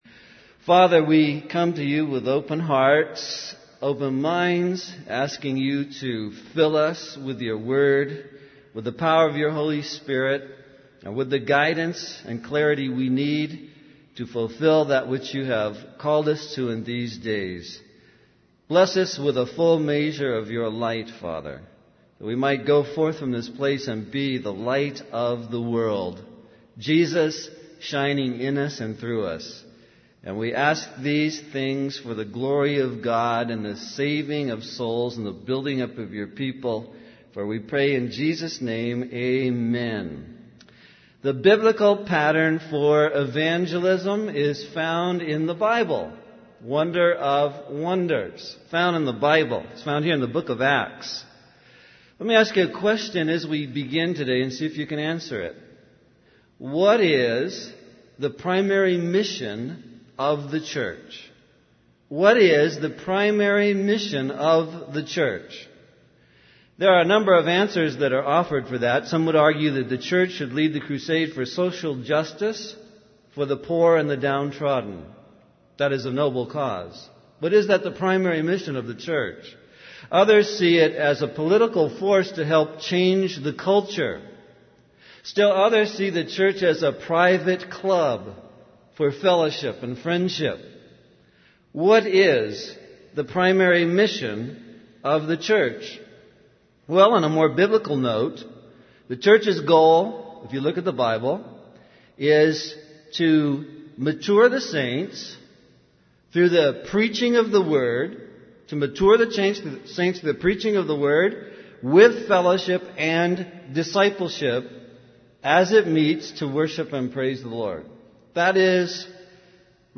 In this sermon, the speaker discusses the biblical pattern for evangelism. He highlights eight key elements: purity, power, persecution, provision, proclamation, protection, pain, and perseverance. The speaker emphasizes the importance of not watering down the word of God, even in the face of trouble and imprisonment.